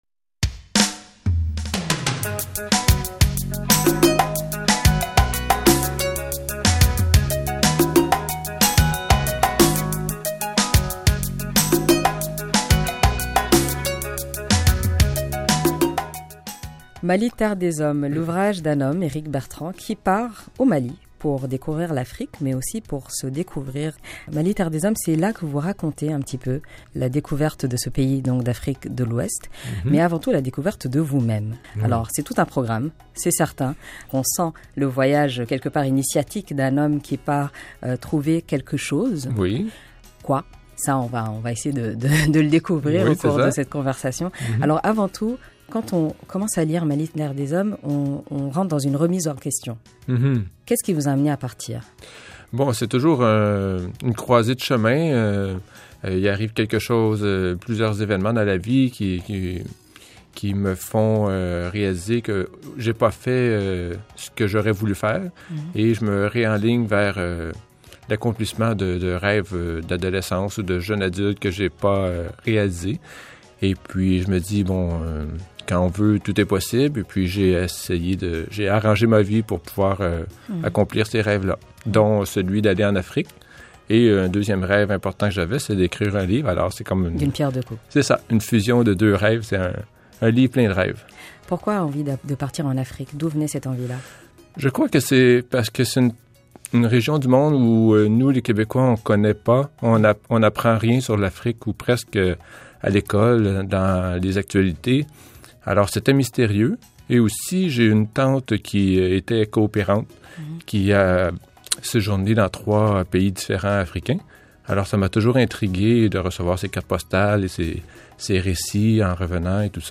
Bousculé par une crise de la quarantaine le poussant à remettre en question son quotidien, il réalise enfin son rêve – découvrir l’Afrique – et revient au Québec, complètement changé. Découvrez son voyage, son expérience d’étranger au Mali (et à Rivière-du-Loup…) et sa découverte de soi à travers son voyage dans l’entrevue qu’il a accordée à Mopaya.